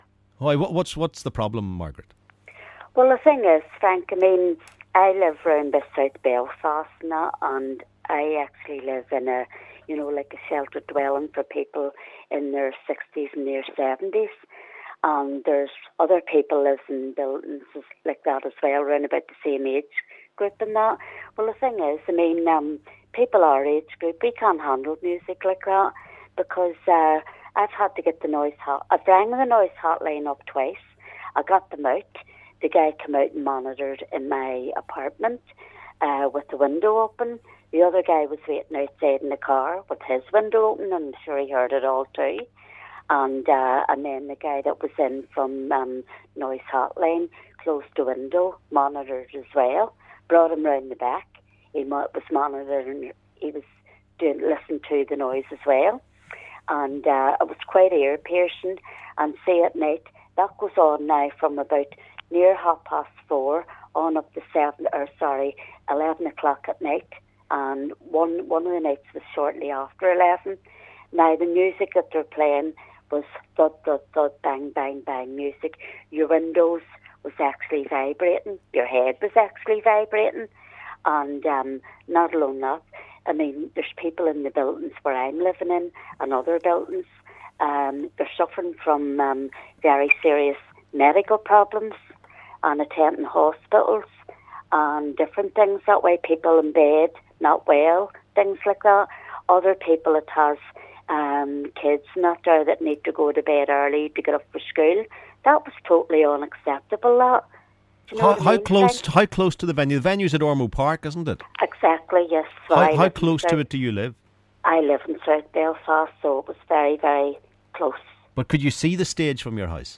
LISTEN ¦ One caller says she moved to her sister's to avoid Belsonic noise